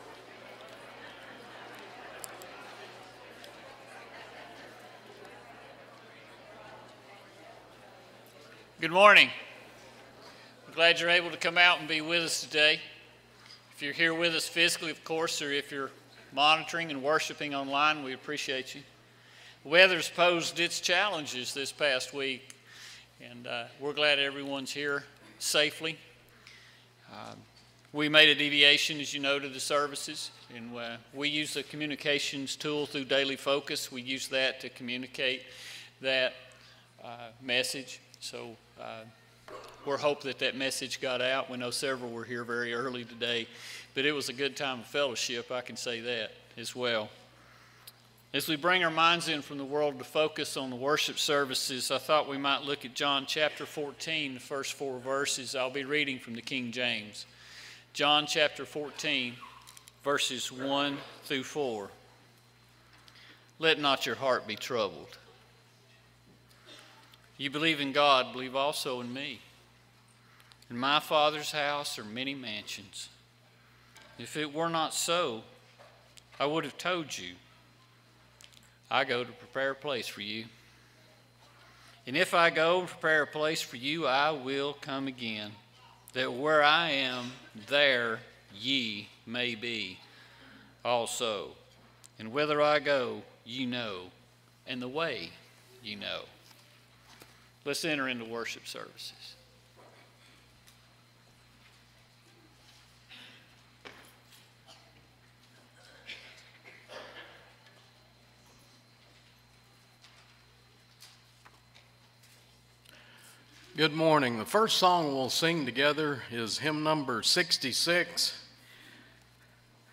Luke 6:46, English Standard Version Series: Sunday AM Service